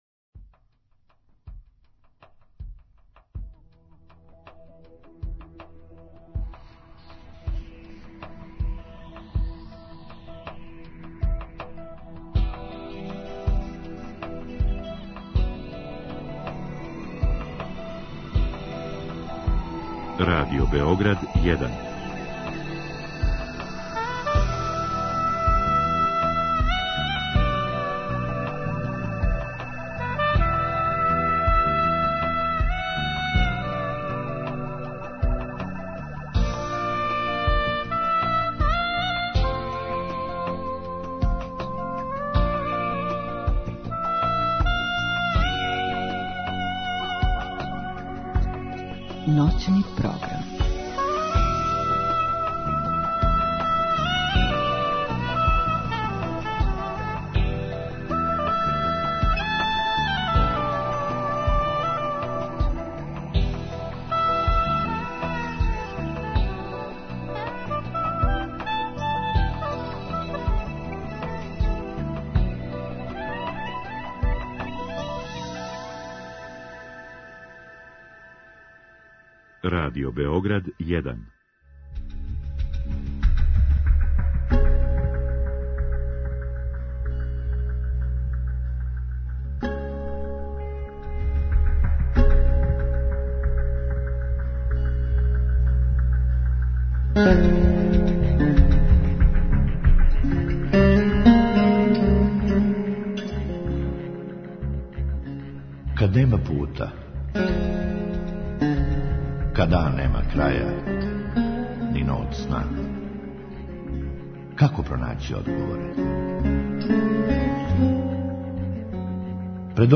У другом сату слушаоци могу поставити питање гошћи у вези са темом, путем Инстаграма или у директном програму.